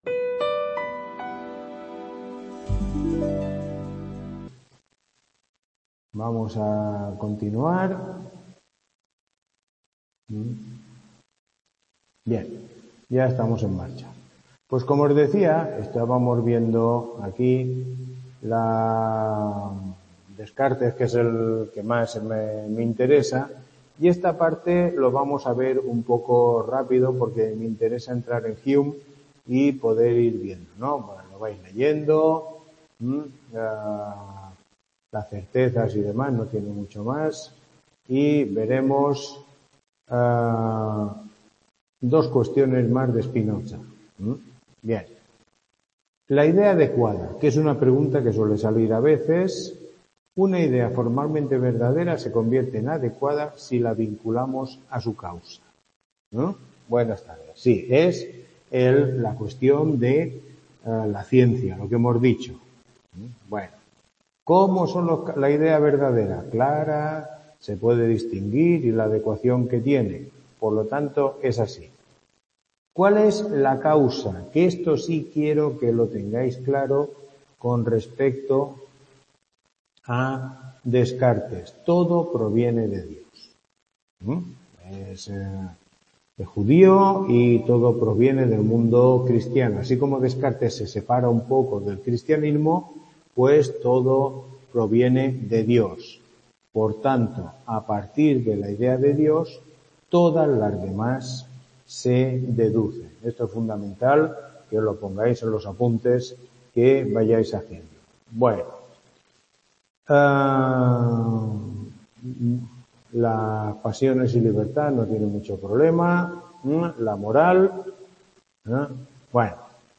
Tutoría 02